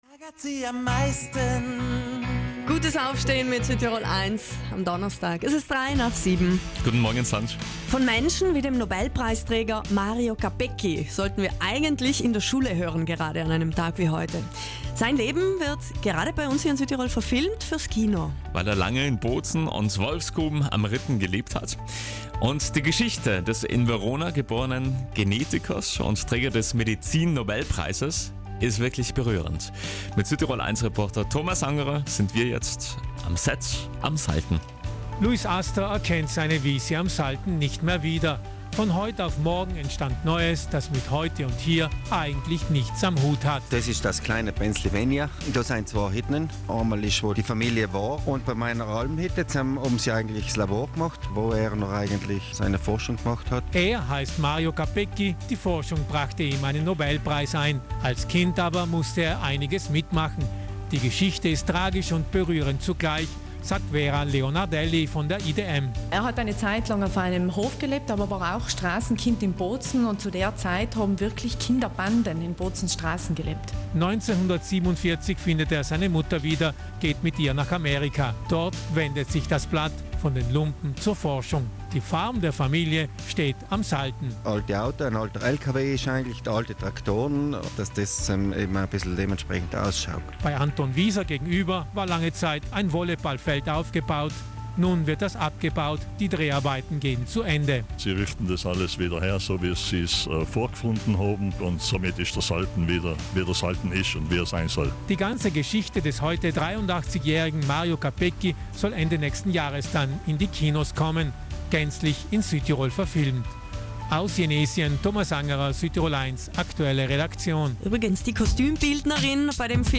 Das Filmset am Salten Das Filmset am Salten zurück 1 /3 vor Audiobeitrag anhören stopp 00:00 / 02:08 Ihr Webbrowser kann den Audiobeitrag leider nicht direkt abspielen. zurück drucken